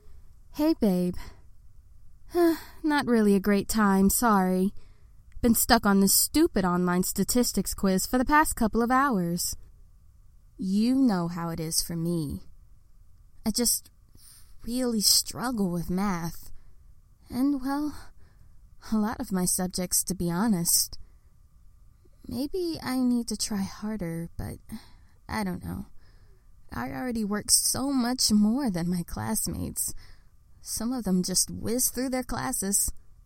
Intro-Sample-Bass-2.mp3